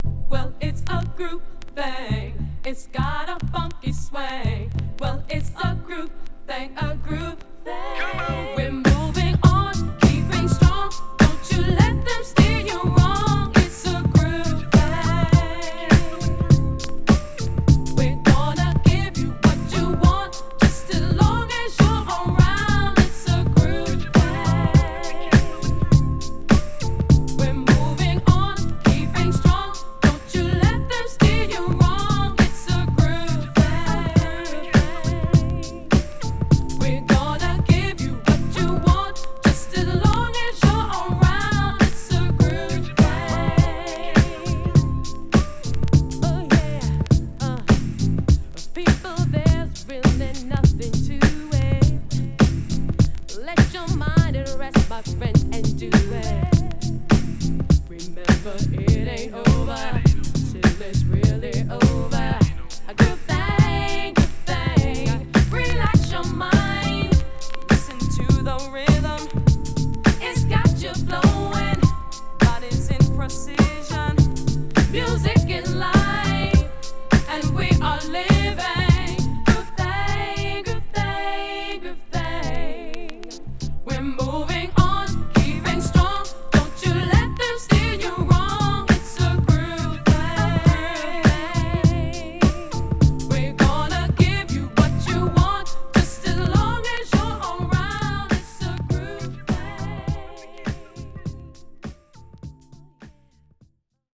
HIP HOP SOULを軸としながらもスムースでジャジーなサウンドが新鮮だった名作！